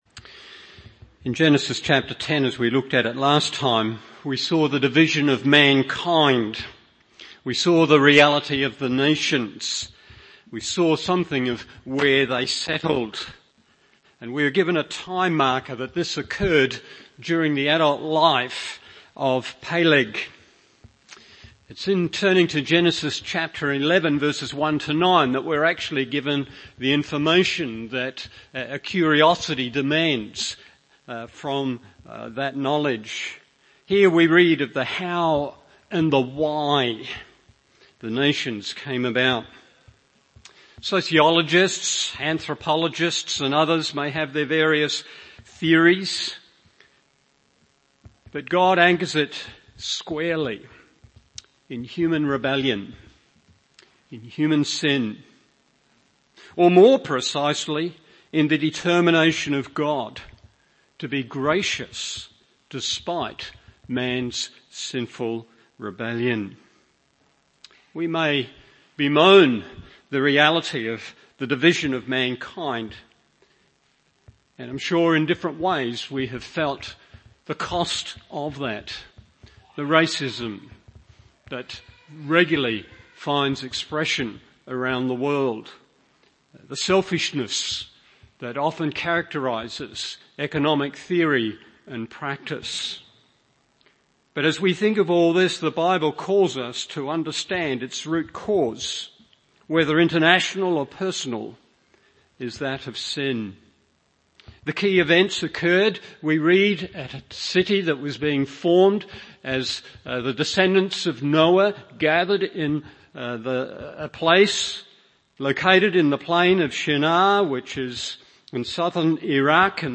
Morning Service Genesis 11:1-9 1. Man 2. Madness 3.